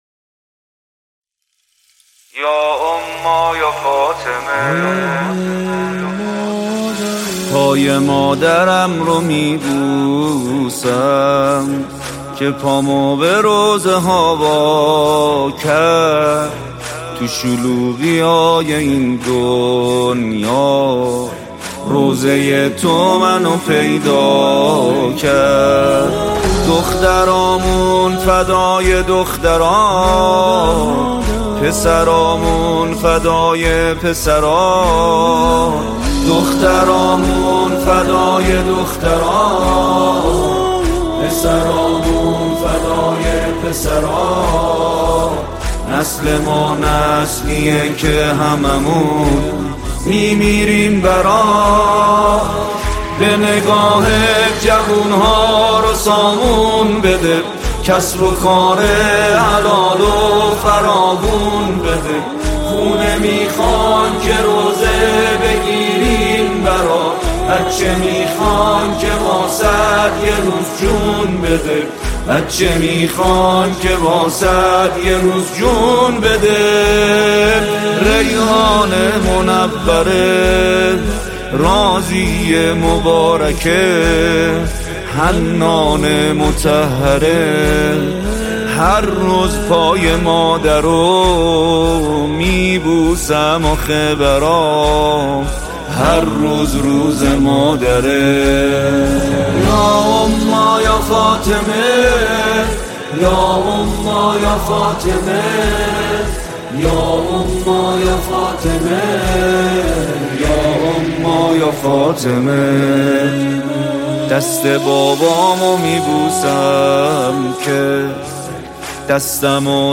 مذهبی
مداحی استودیویی